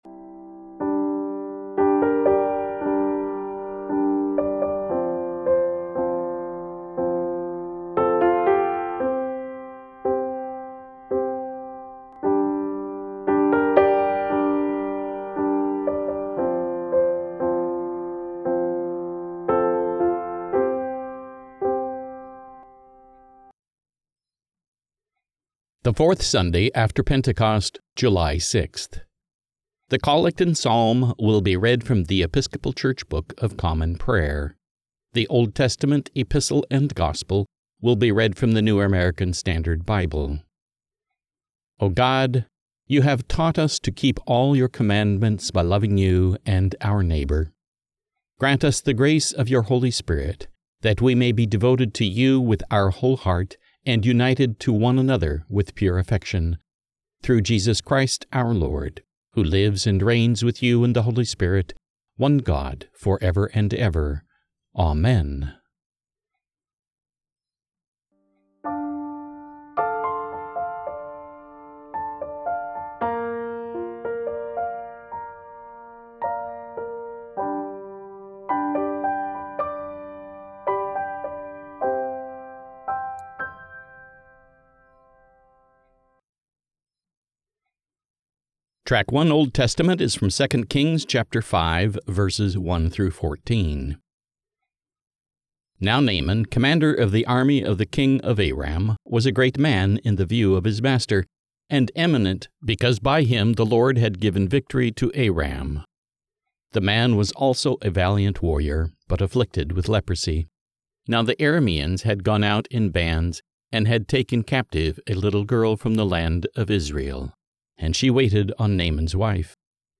The Collect and Psalm will be read from The Episcopal Church Book of Common Prayer
The Old Testament, Epistle and Gospel will be read from the New American Standard Bible